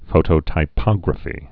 (fōtō-tī-pŏgrə-fē)